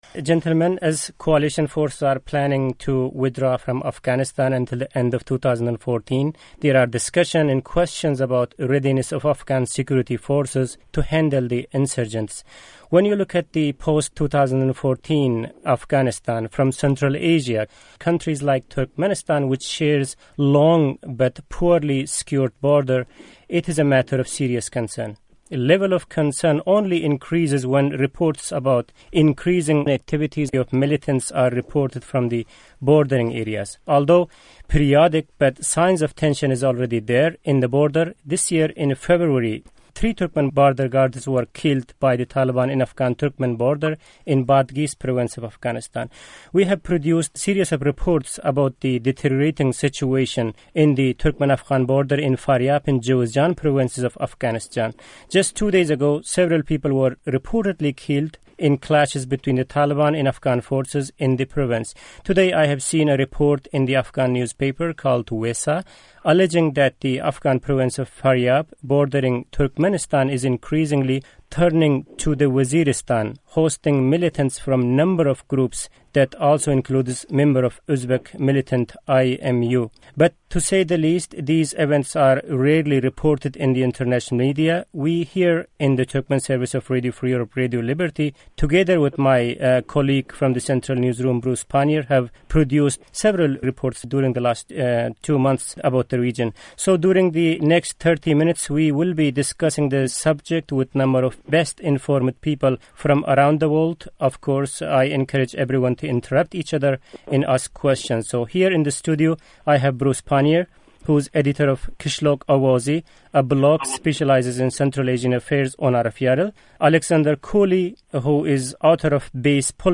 Azatlyk, May 2014 -- TKM Roundtable